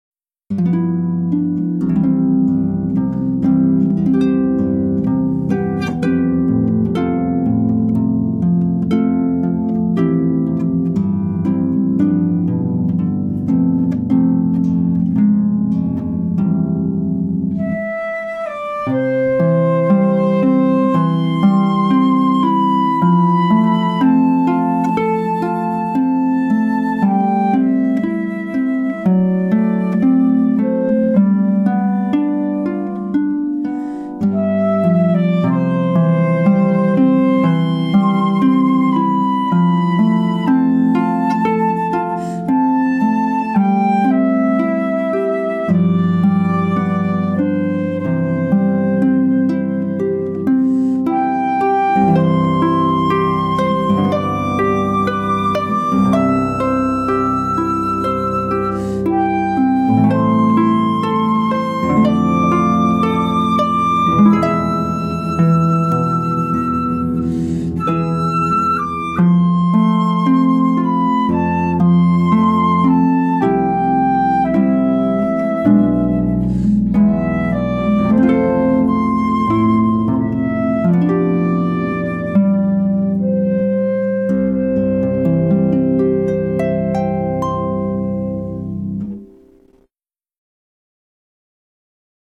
FLUTE AND HARP REPERTOIRE
TRADITIONAL MUSIC